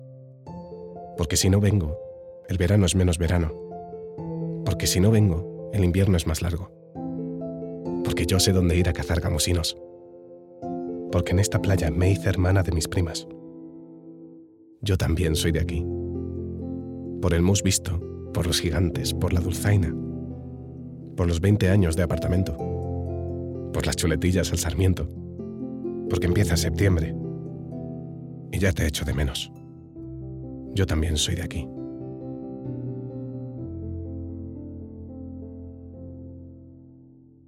Narration
Baritone